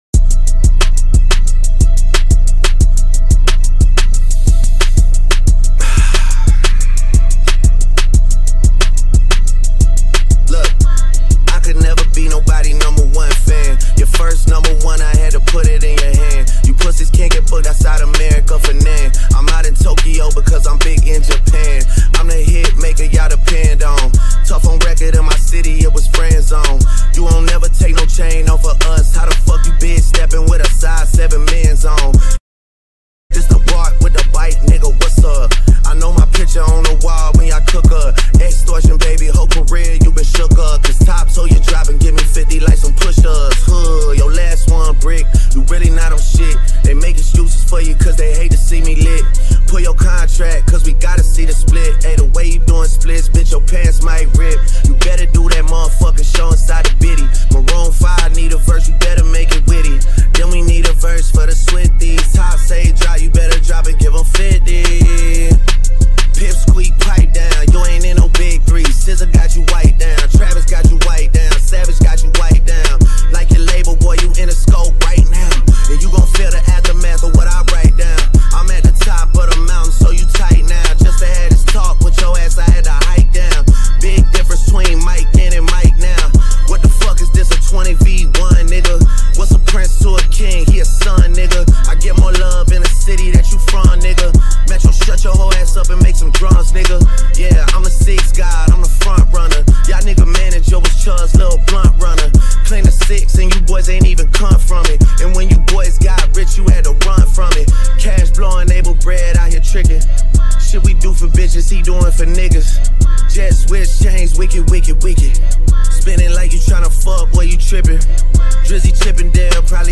دانلود آهنگ سبک هیپ هاپ